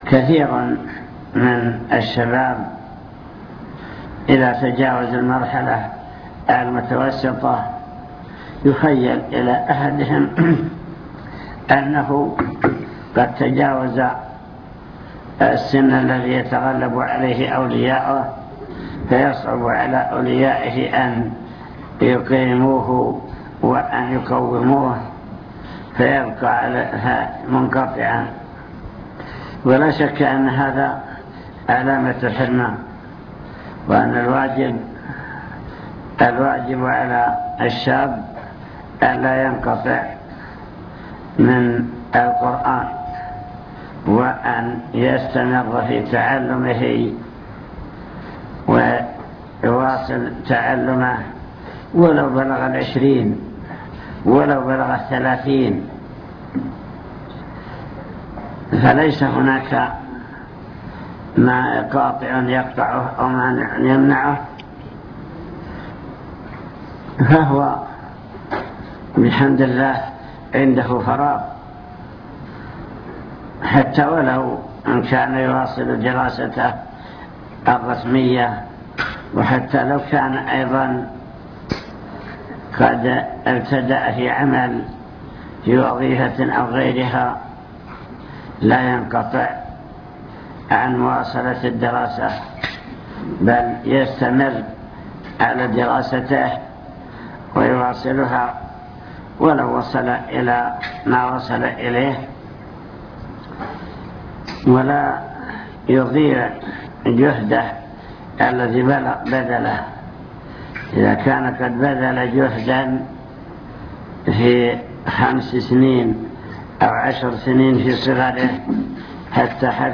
المكتبة الصوتية  تسجيلات - لقاءات  لقاء جماعة تحفيظ القرآن بالحريق